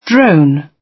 Drone.wav